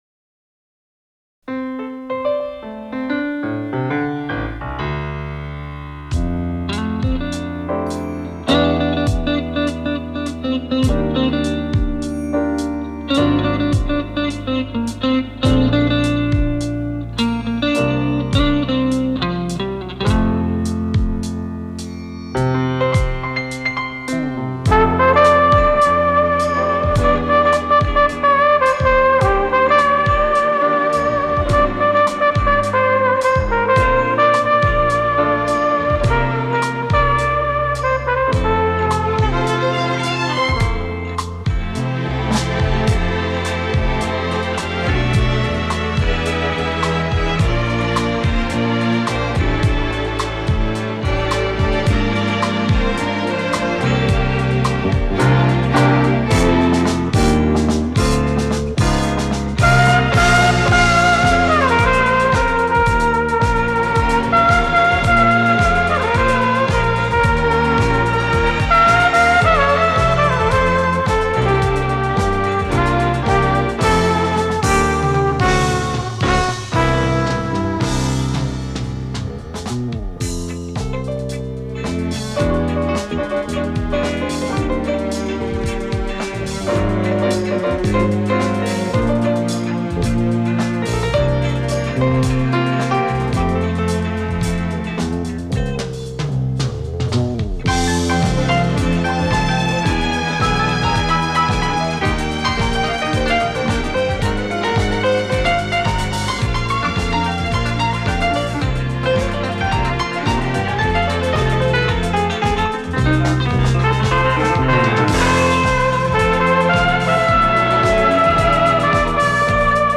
Вечерние мелодии с участием зарубежных исполнителей.